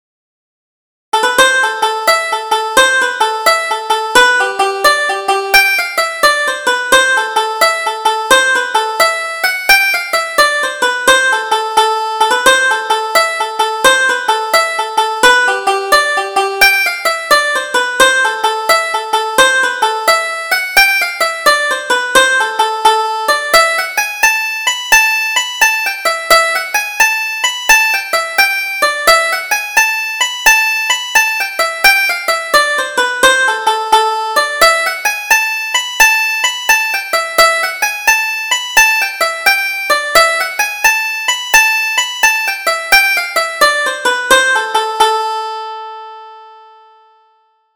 Double Jig: The Dancing Master